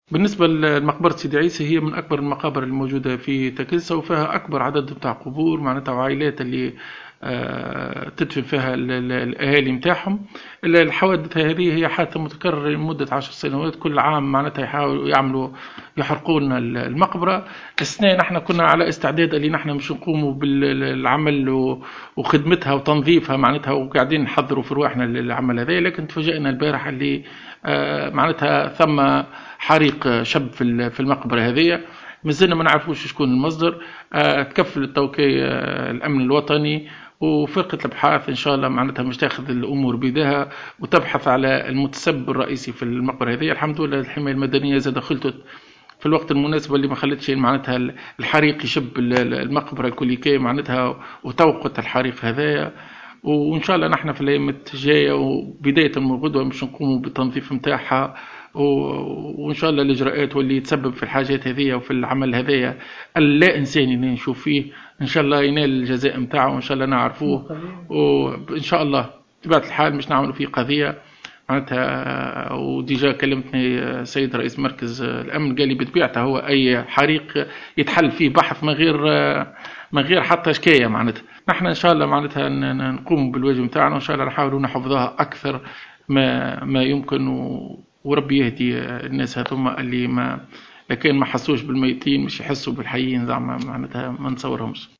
تصريح رئيس بلدية تاكلسة وليد العميري